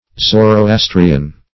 Zoroastrian \Zo`ro*as"tri*an\, a.
zoroastrian.mp3